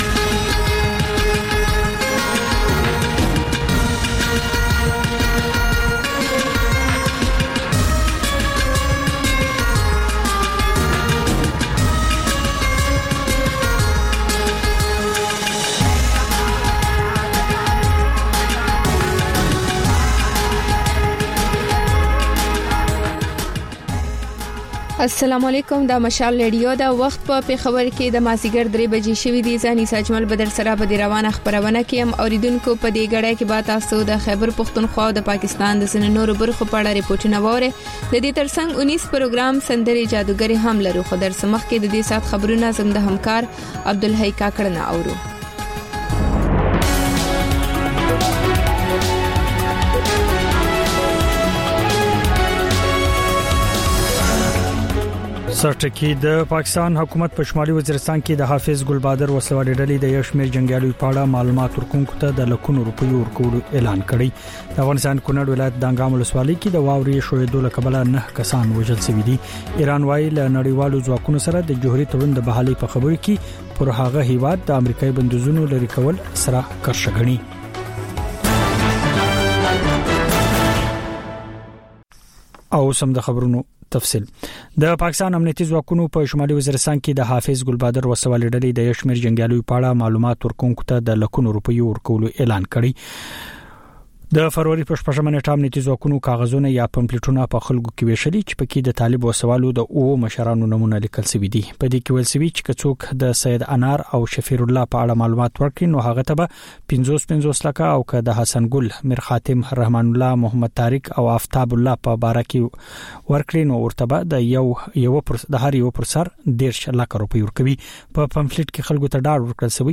د مشال راډیو درېیمه یو ساعته ماسپښینۍ خپرونه. تر خبرونو وروسته، رپورټونه، شننې، او رسنیو ته کتنې خپرېږي.